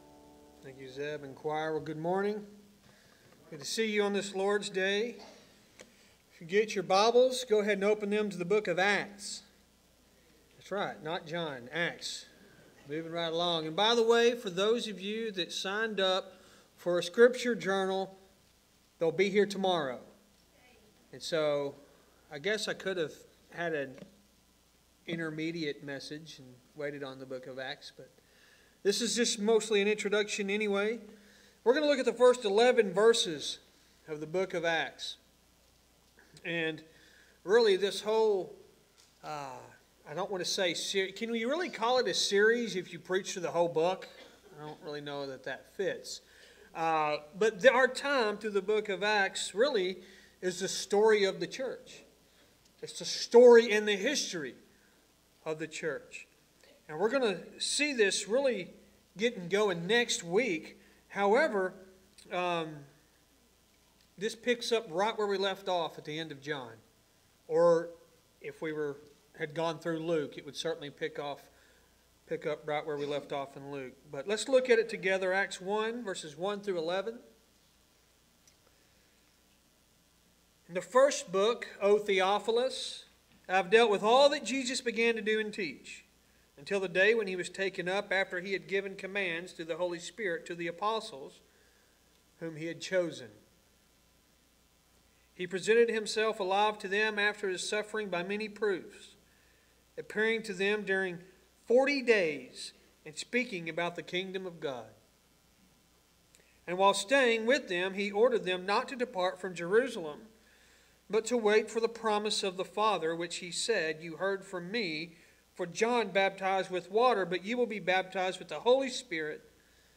Sermons | Lake Athens Baptist Church